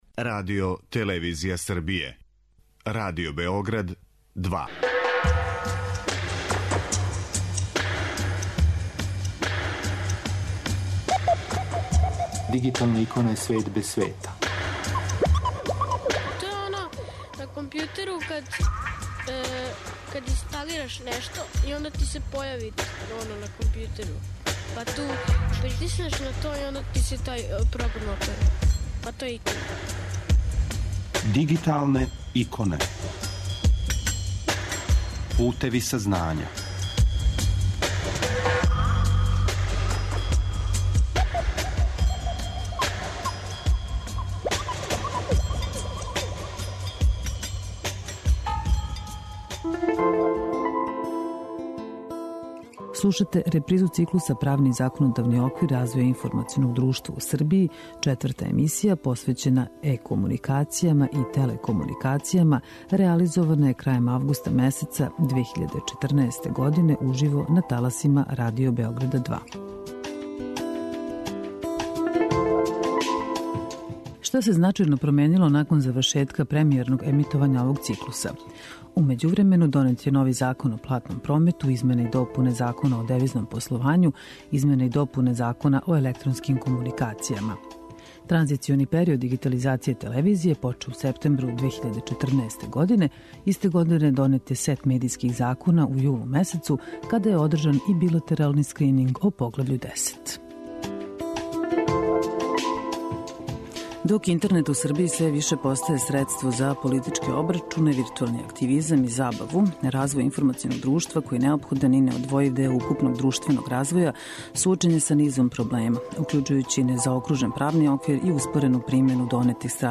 Четврта емисија, посвећена е-комуникацијама и телекомуникацијама, реализована је крајем августа месеца 2014, уживо, на таласима Радио Београда 2.